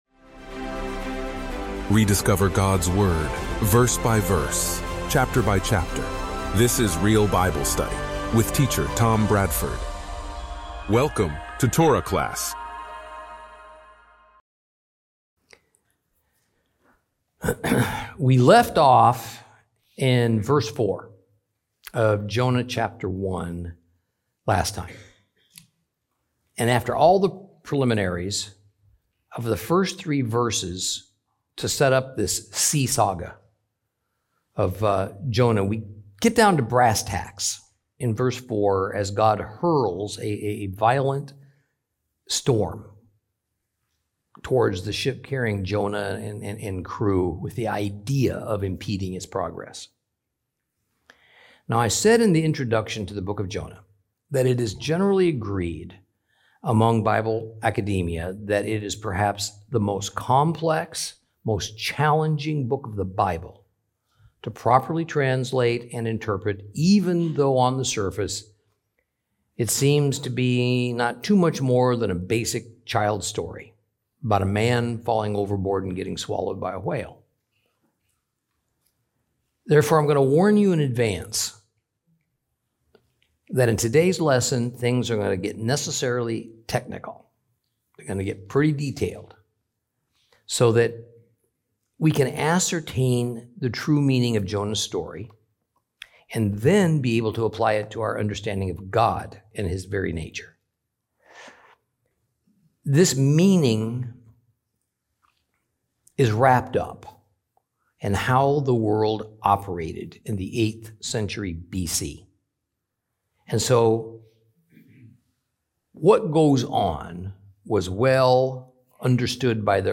Teaching from the book of Jonah, Lesson 3 Chapter 1continued.